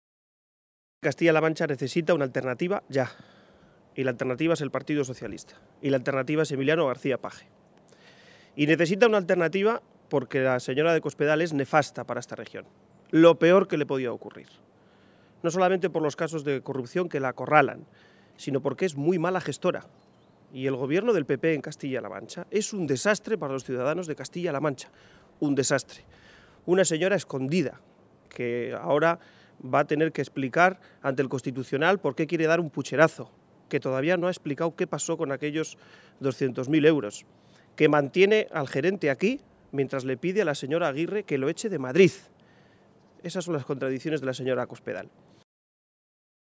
García-Page se pronunciaba de esta manera esta mañana, en Toledo, en una comparecencia ante los medios de comunicación durante la reunión que ha dirigido junto al secretario de organización federal del PSOE, César Luena.